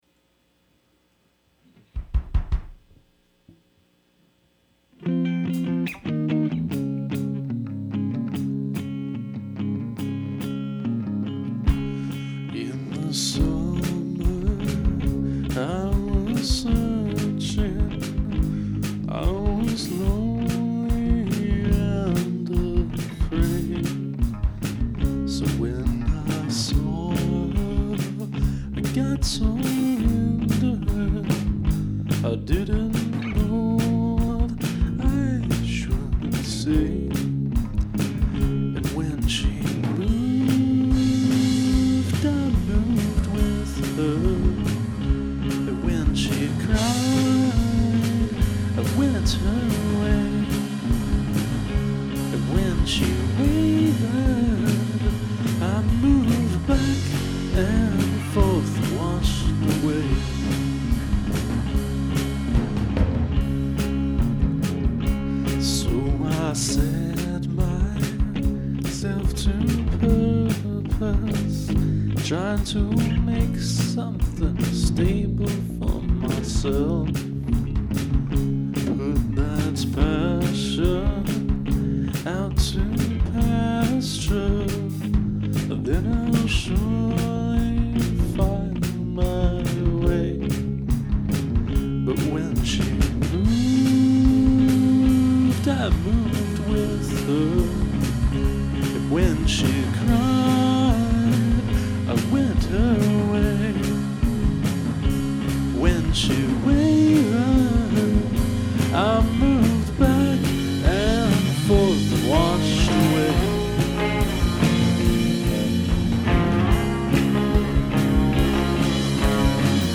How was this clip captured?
On the solo it's dimed with reverb, somewhere less extreme for the rest. Probably eq'd each of those a bit as well.